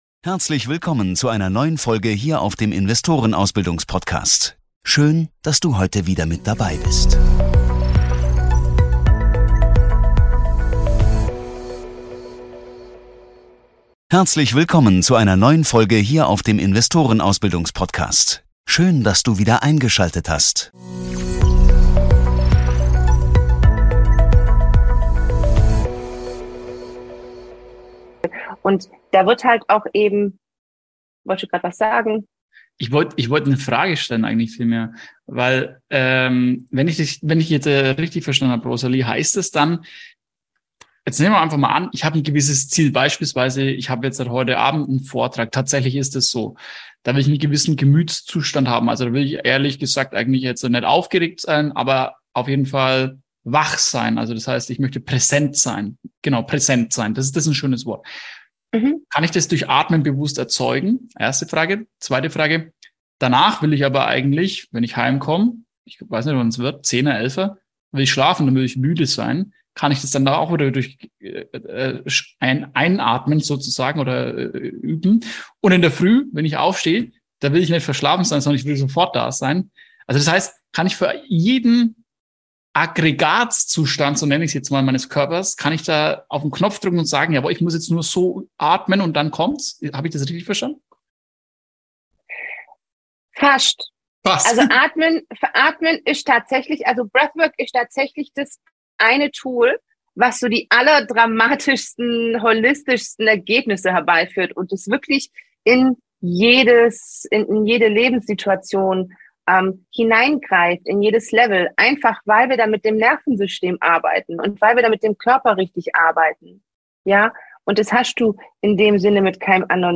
Beschreibung vor 3 Jahren Dieses Interview besteht aus 2 Teilen, der erste Teil kam bereits letzten Dienstag, den 04.10.2022. Es geht um Breathwork, also Atemtechniken, mit denen ihr erfolgreicher und gesünder durch den Alltag kommt und in schwierigen Situationen die Kontrolle behaltet.